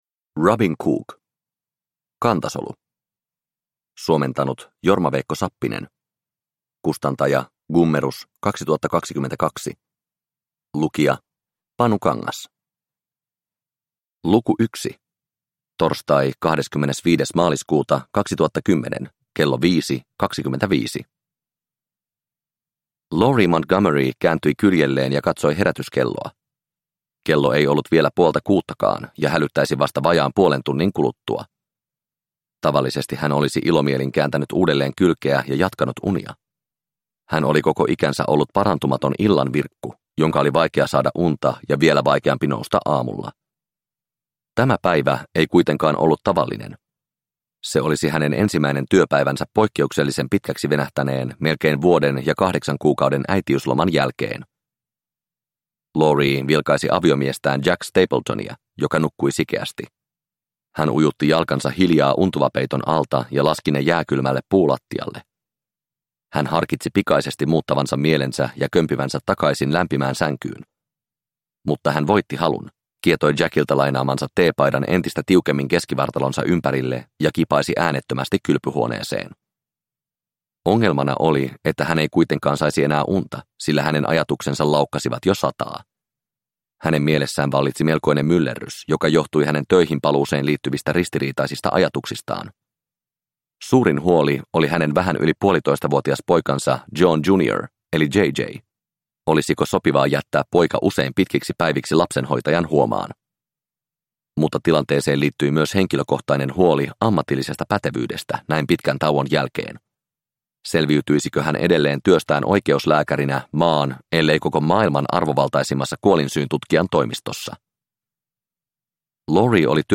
Kantasolu – Ljudbok – Laddas ner